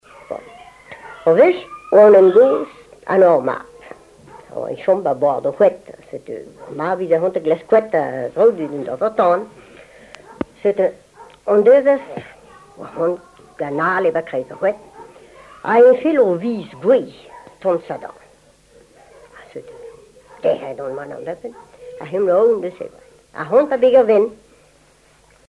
Genre conte
Catégorie Récit